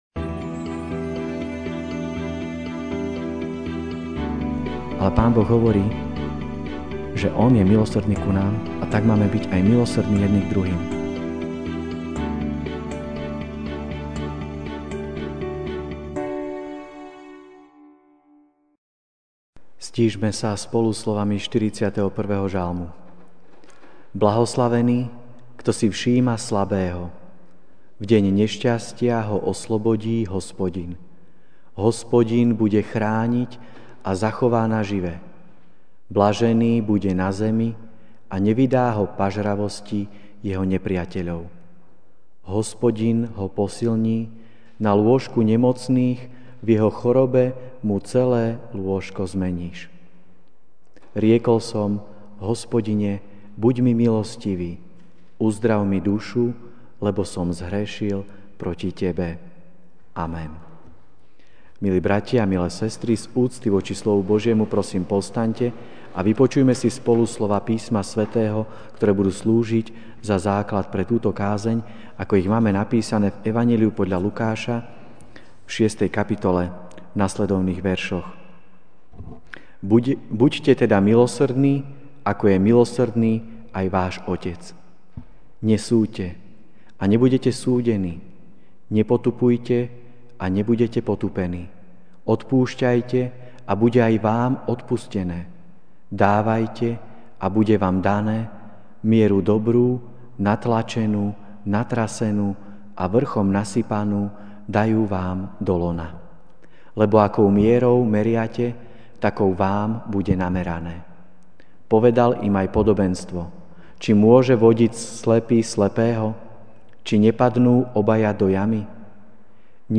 Večerná kázeň: Milosrdenstvo a odpustenie (Lk. 6, 36-42) Buďte teda milosrdní, ako je milosrdný aj váš Otec.